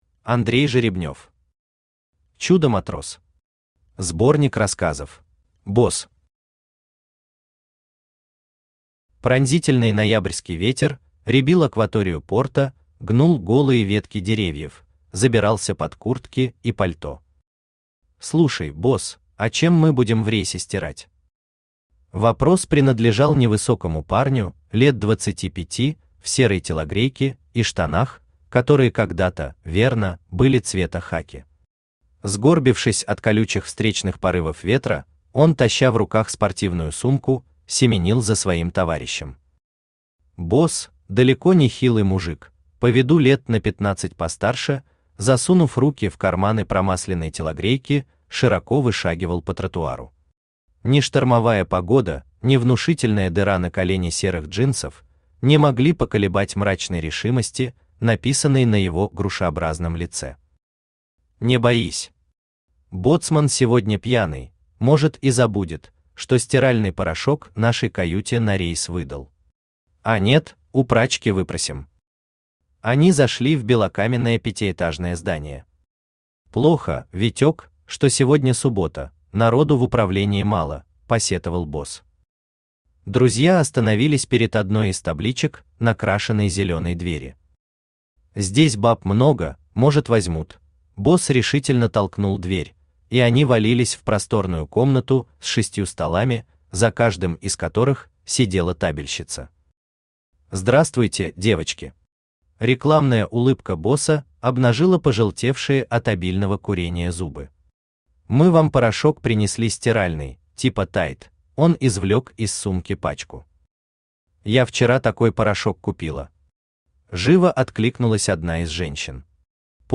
Аудиокнига Чудо-матрос. Сборник рассказов | Библиотека аудиокниг
Сборник рассказов Автор Андрей Жеребнев Читает аудиокнигу Авточтец ЛитРес.